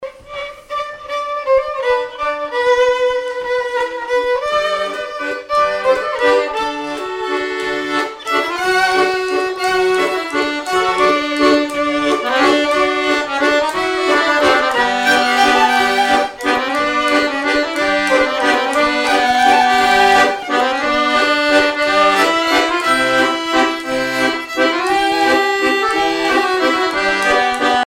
Miquelon-Langlade
danse : valse
violon
Pièce musicale inédite